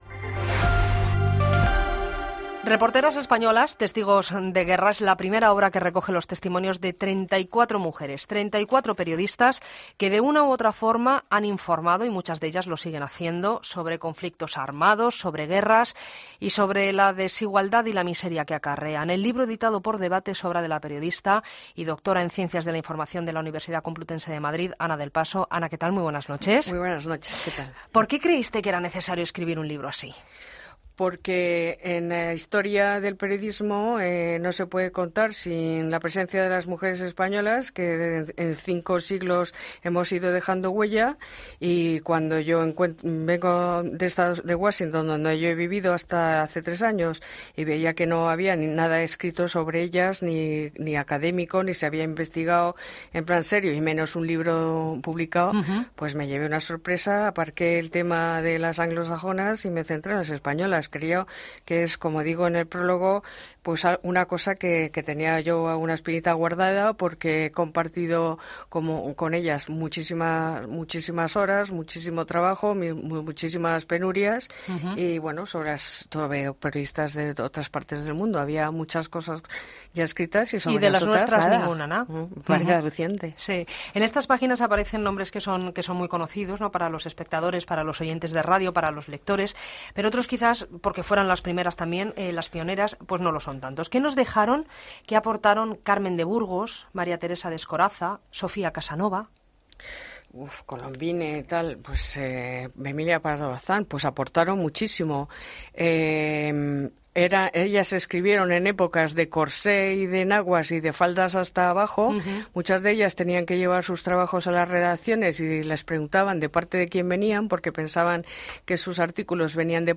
Presentado por Juan Pablo Colmenarejo, uno de los periodistas más prestigiosos de la radio española, el programa es una de las marcas propias de COPE que repasa desde un punto de vista diferente la actualidad política y económica.